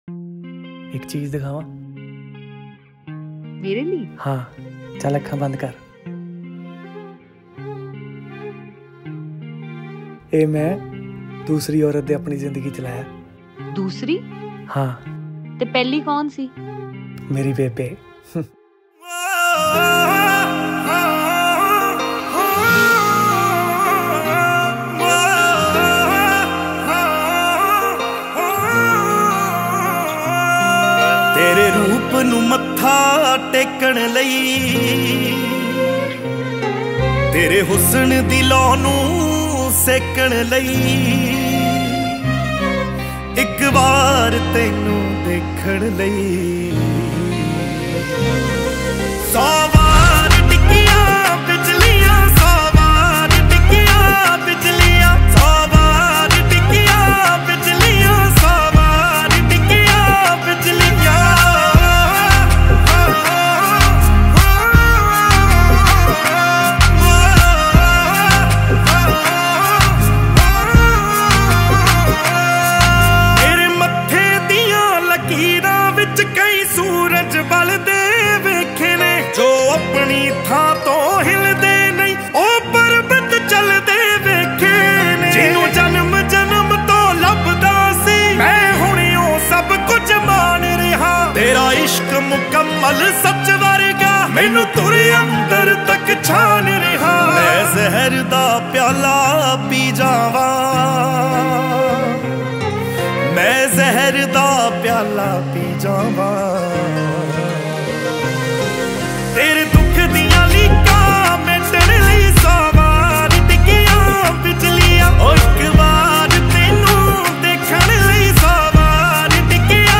Category: New Punjabi Mp3 Songs